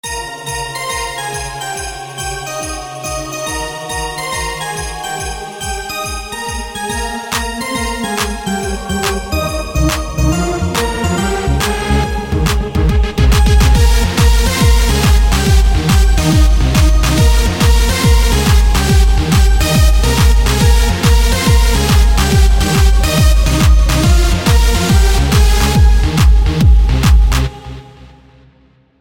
Kategorien: Weihnachten